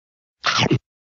Munch (eating biting) Meme sound effects free download